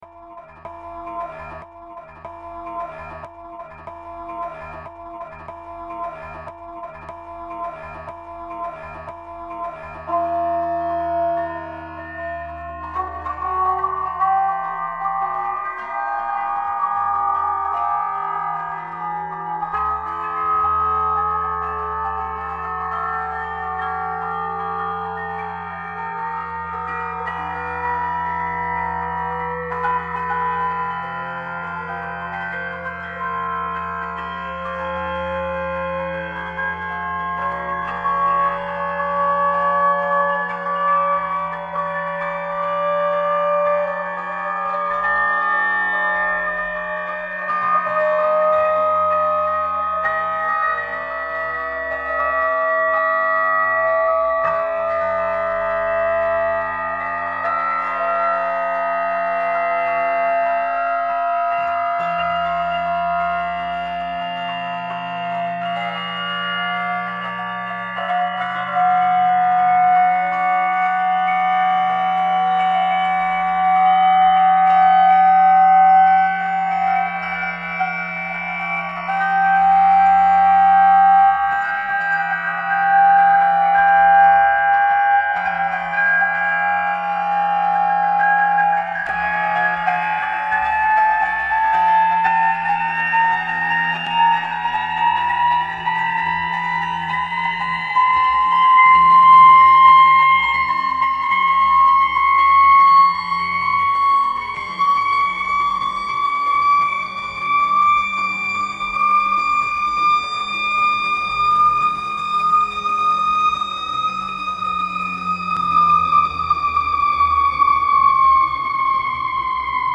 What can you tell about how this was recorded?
Chicago lockdown sound reimagined